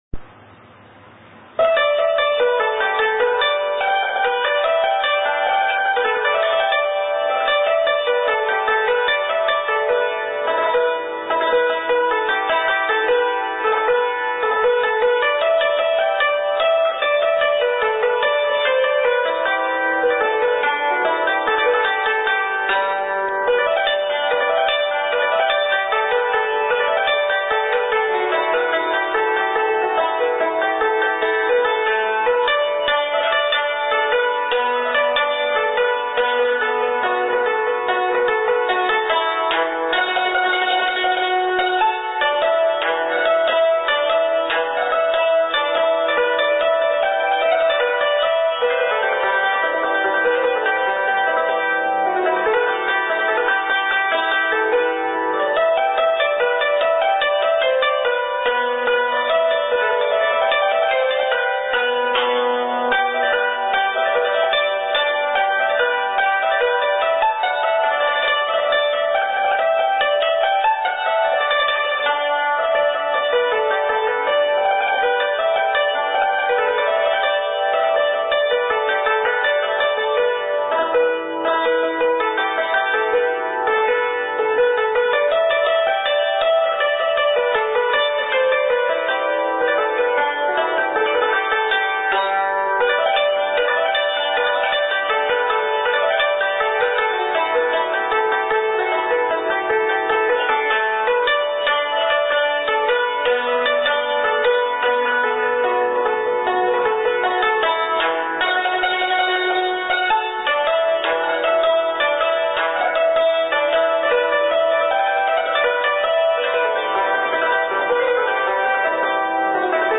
廣東音樂娛樂昇平
作曲：丘鶴儔       演奏：揚琴
《娛樂昇平》旋律流暢，一氣呵成，用士工線構成，曲調清新活潑、流麗秀美、旋律悠揚、音調清新。每個音符都充滿歡騰、快樂。